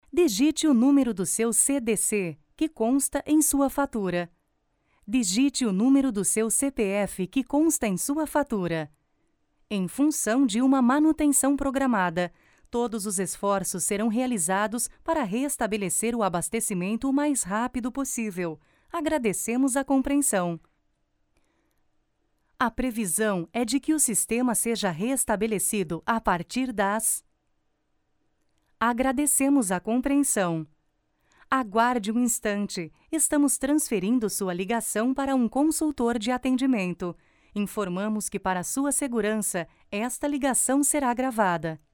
Sprechprobe: Werbung (Muttersprache):
The texts are really interpreted in the most correct way for the purpose of destination, the most jovial tone, retailer, and also interpretations for interaction with kids, happy, relaxed and dynamic reading, even the most serious, ceremonial readings and detailed jobs.